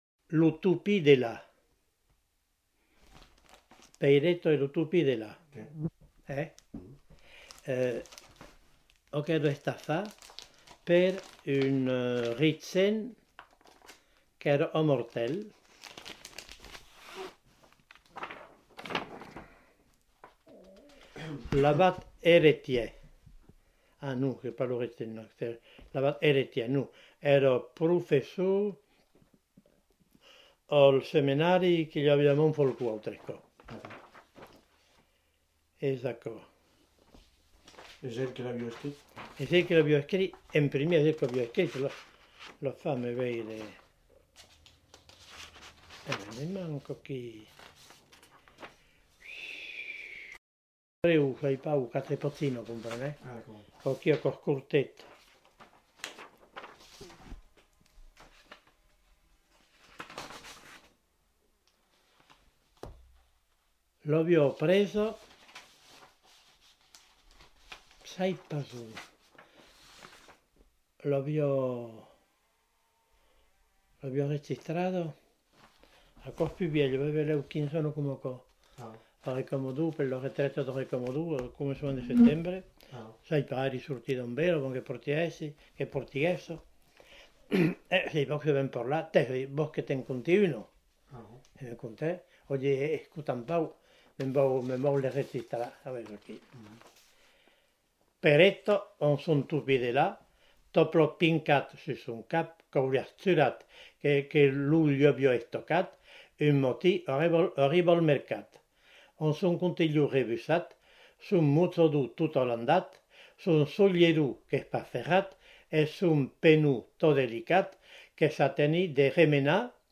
Genre : conte-légende-récit
Type de voix : voix d'homme Production du son : lu